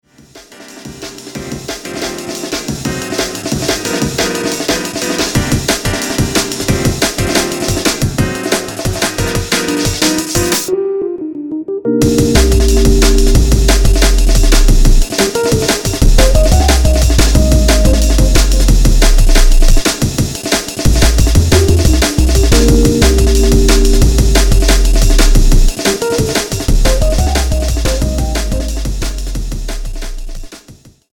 鍵盤楽器とアーメンブレイクが響きあうリキッドファンク風ドラムンベースです。
パッドとピアノとエレピとベースとドラムのフレーズを流用しております。